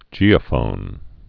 (jēə-fōn)